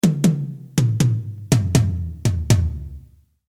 Satter Sound mit extrem kurzem Sustain und knackigem Attack
Dieser Klang erfreut sich bei Hardrock- und Heavy Metal-Drummern großer Beliebtheit, da auch bei schnellen Tomfills jeder Akzent deutlich hörbar bleibt.
Sie produzieren dann immer noch einen satten, allerdings jetzt sehr attackbetonten Sound mit relativ kurzem Sustain.
dt_tom03.mp3